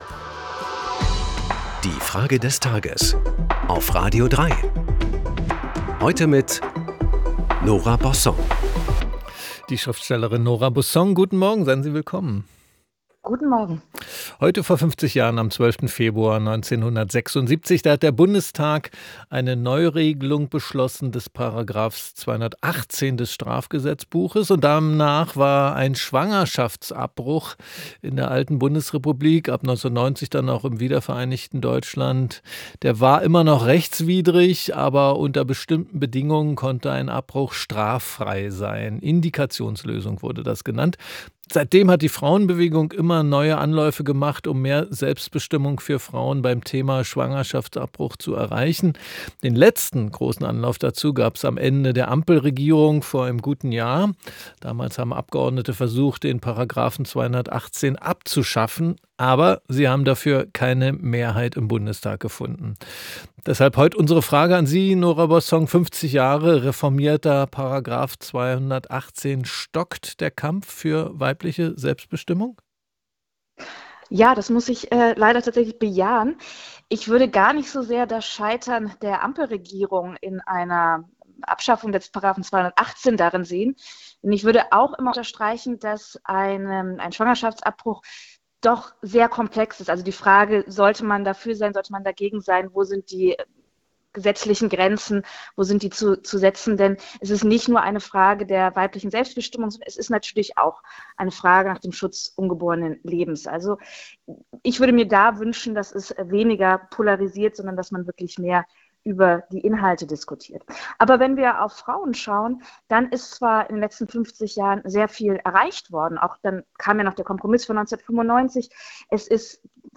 Es antwortet die Schriftstellerin Nora Bossong.